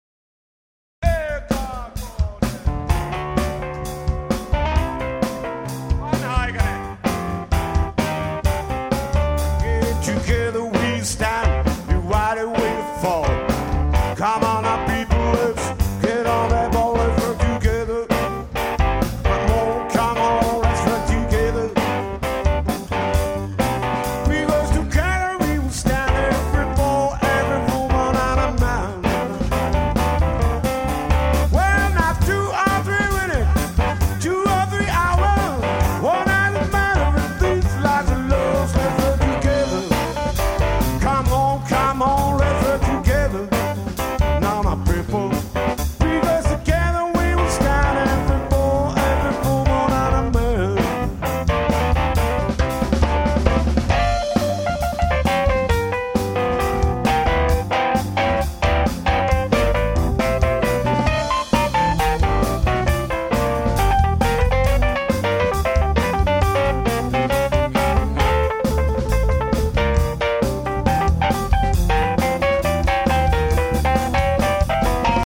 kosketinsoitin & laulu
kitara tai basso & laulu
rummut